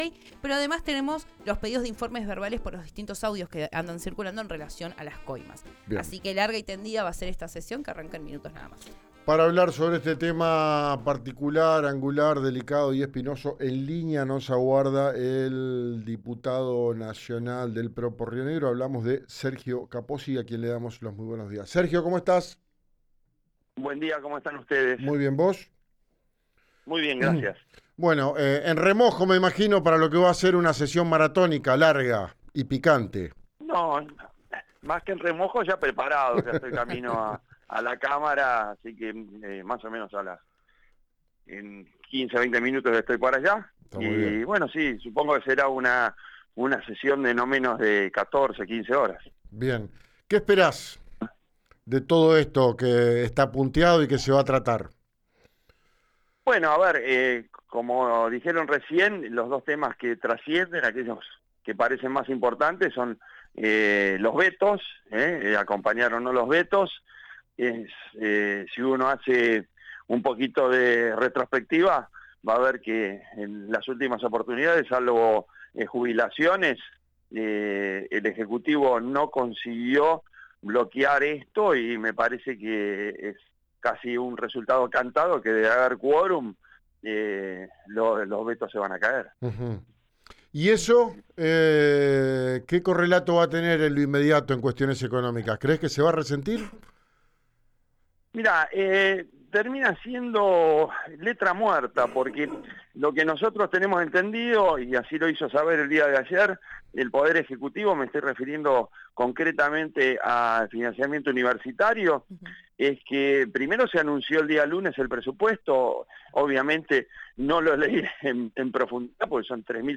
Escuchá a Sergio Eduardo Capozzi, diputado del PRO, en RN Radio